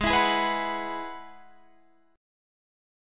G#m7#5 chord